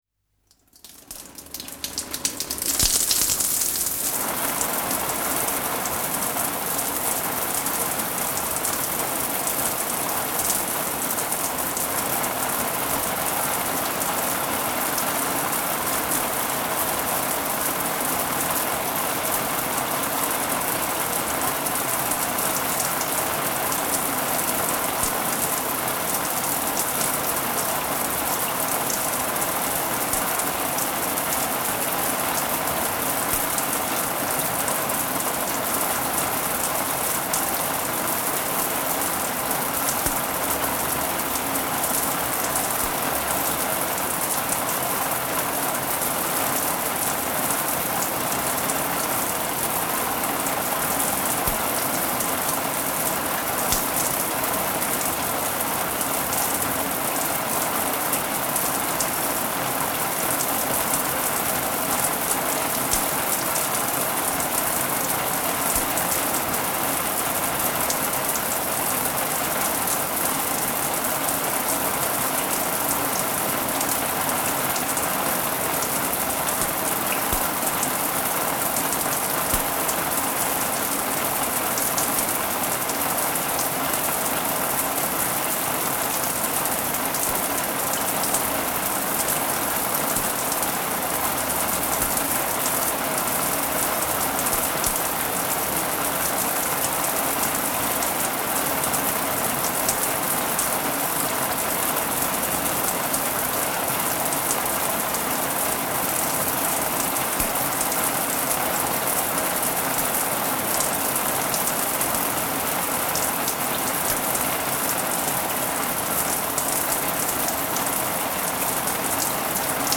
Bij naderend onweer, een herinnering met geluiden
(en luister dan naar de regen en herinner je de letters)
Met dank aan het BBC-geluidsarchief.